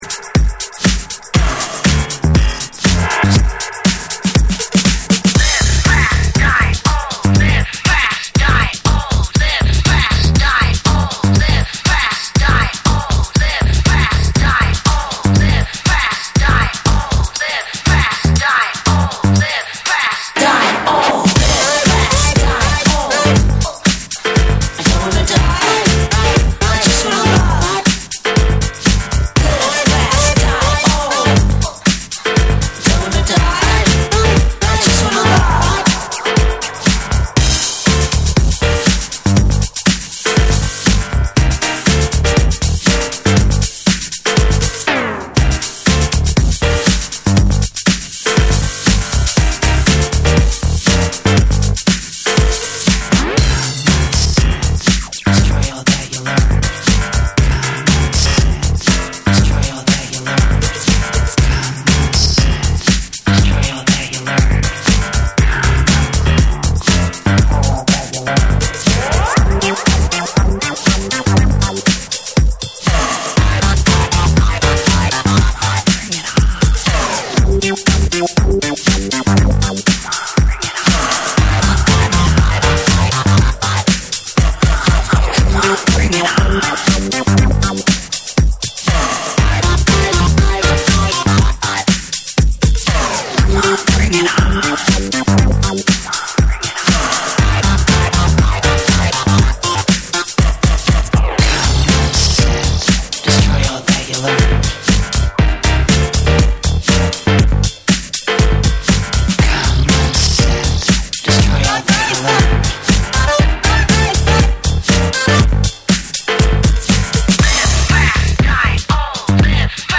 TECHNO & HOUSE / DANCE CLASSICS / DISCO